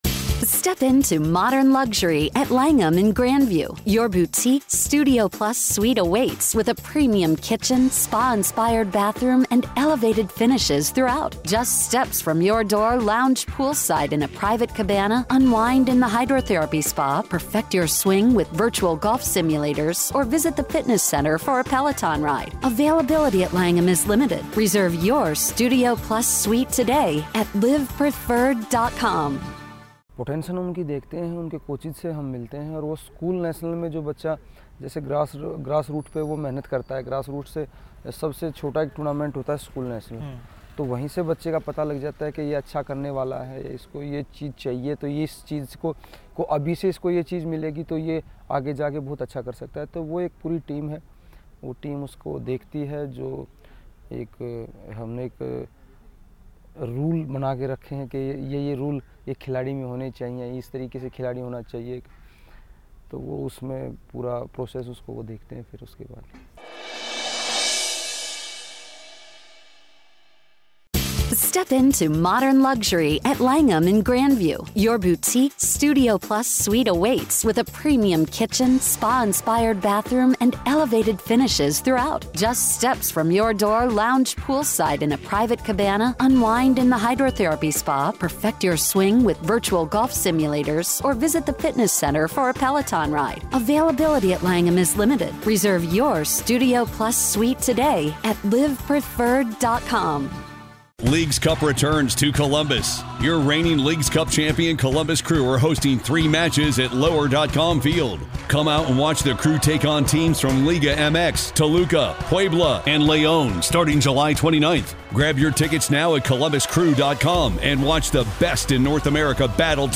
India has been able to produce several world class wrestlers over the past decade. Listen as Sushil Kumar explains the scouting system for young Indian wrestlers.